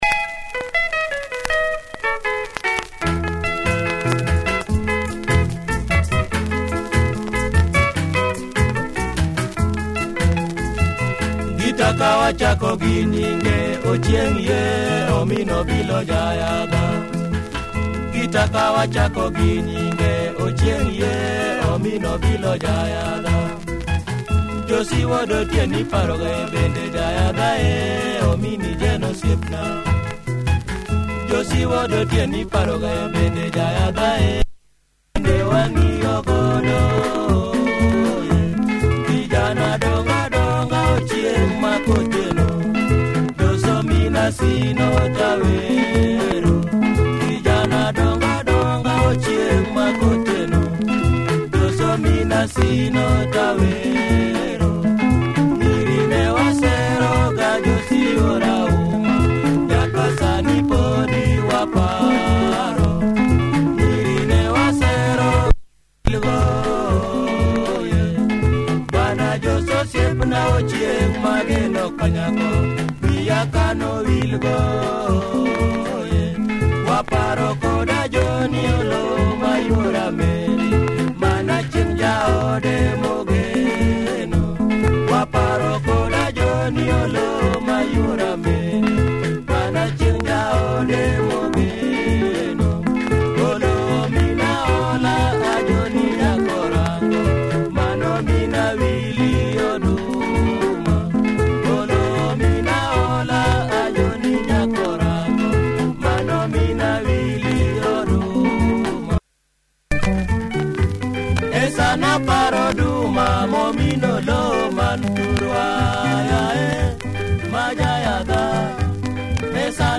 Bouncy Swahili Rumba, nice groove, check audio! https